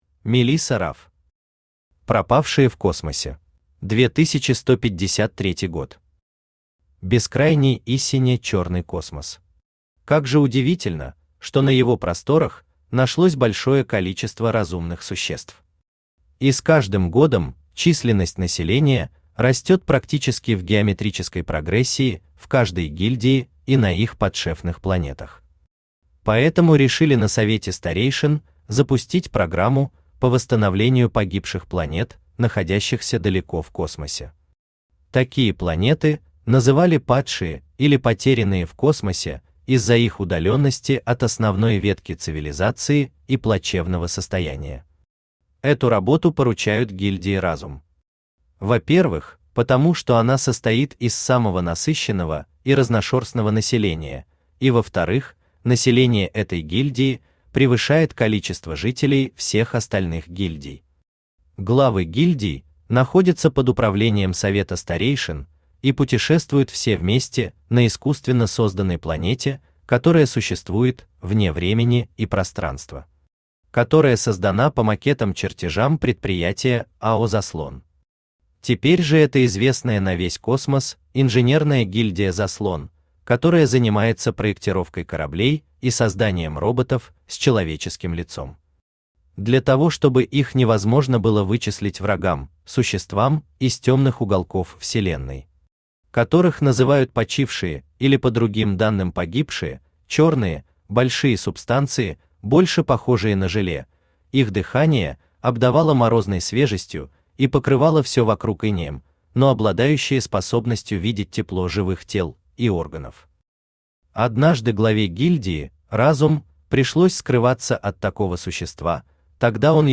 Аудиокнига Пропавшие в космосе | Библиотека аудиокниг
Aудиокнига Пропавшие в космосе Автор Мелисса Раф Читает аудиокнигу Авточтец ЛитРес.